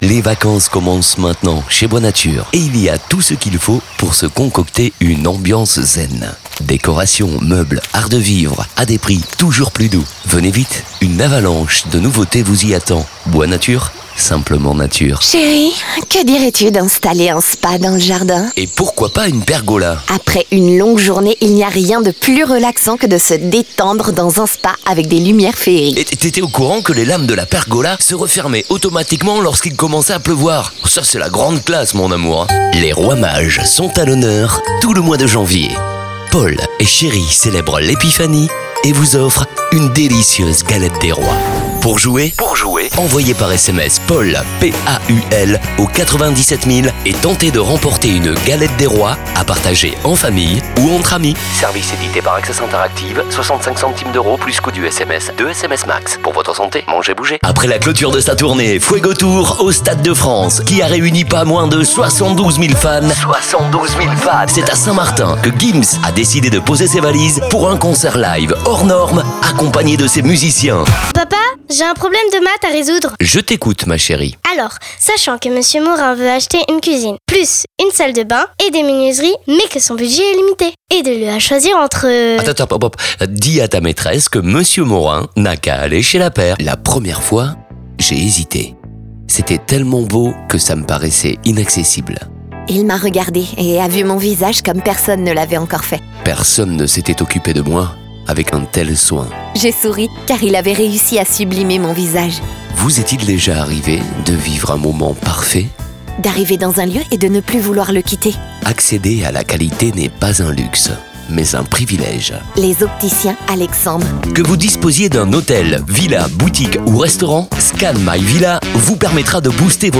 Vocal Age:
45 - Above
My demo reels
demo-voix-masculine-3.mp3